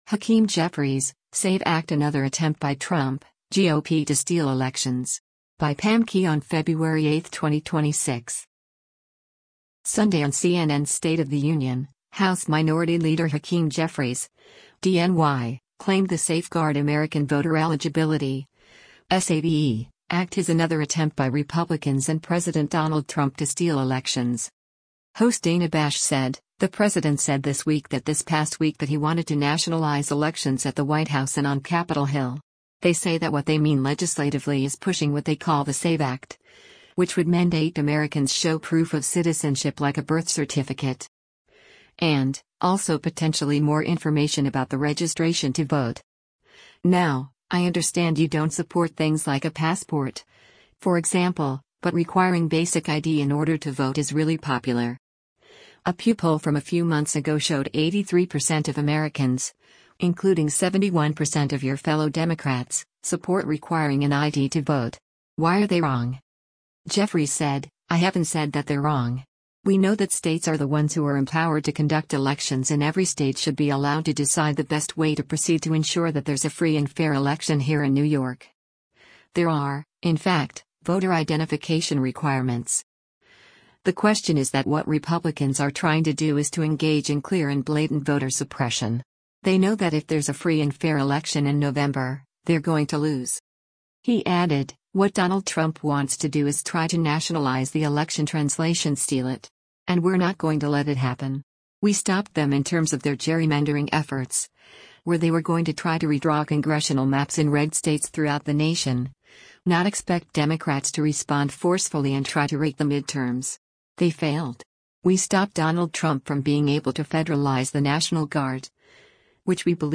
Sunday on CNN’s “State of the Union,” House Minority Leader Hakeem Jeffries (D-NY) claimed the Safeguard American Voter Eligibility (SAVE) Act is another attempt by Republicans and President Donald Trump to steal elections.